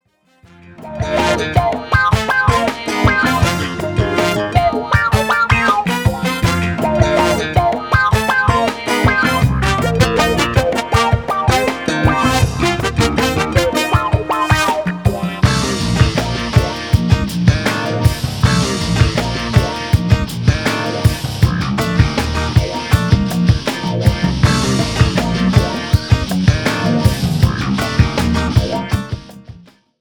FUNK  (2.45)